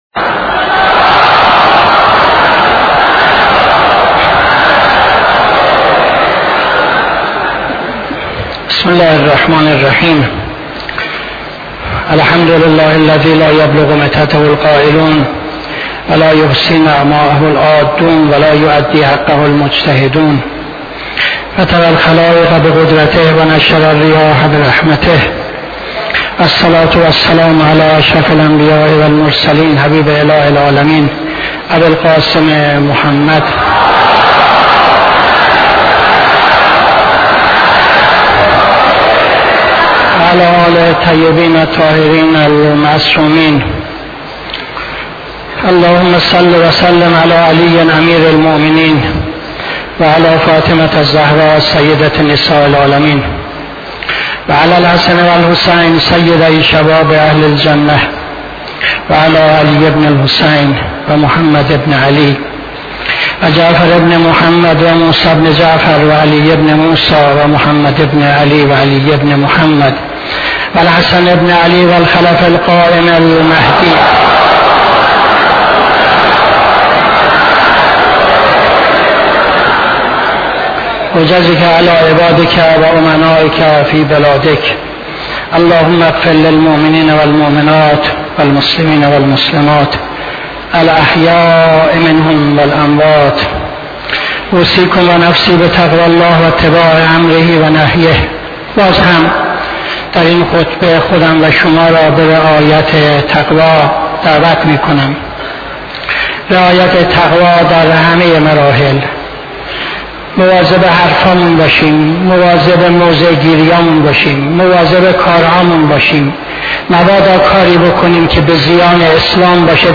خطبه دوم نماز جمعه 22-03-77